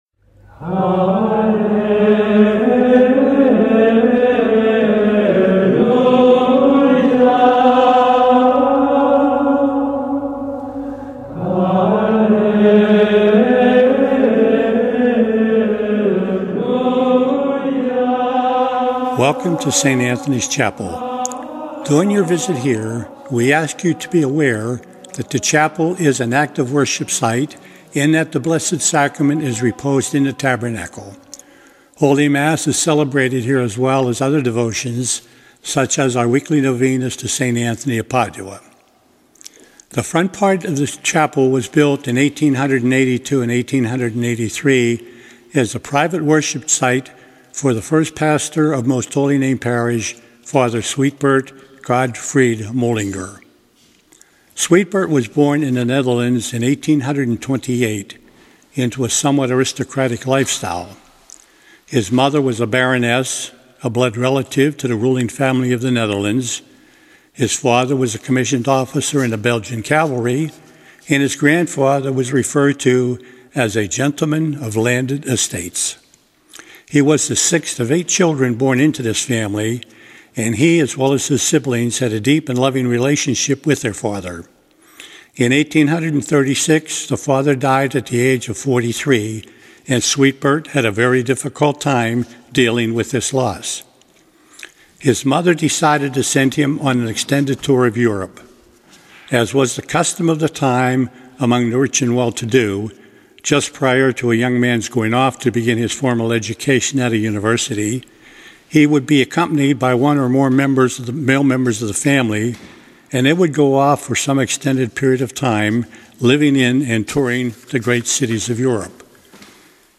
Click the graphic above to hear an audio tour of the chapel; this is the same recording that can be heard in the chapel on most weekdays
Saint Anthony Chapel Tour.mp3